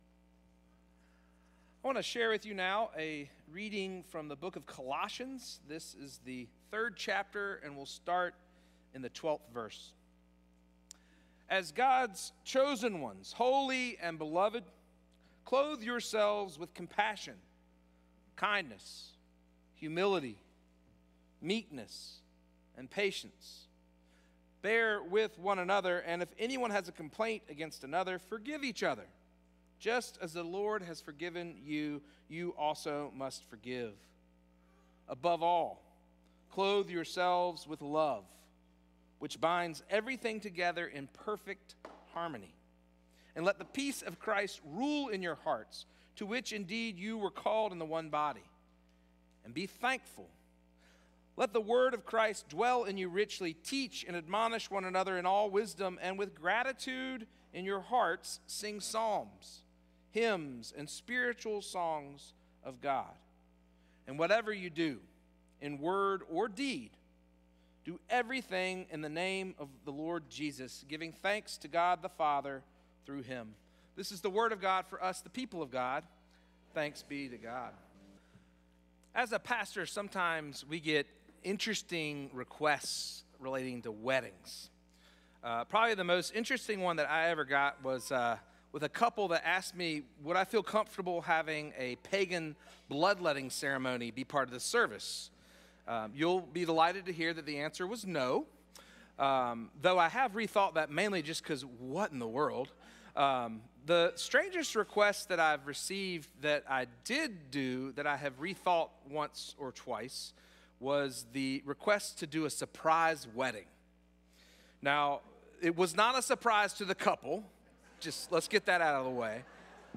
First Cary UMC